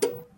crt_shutdown.mp3